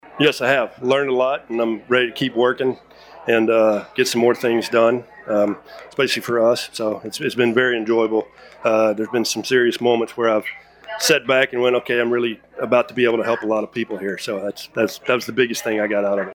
We also asked him if he was enjoying his time in the legislature . . . .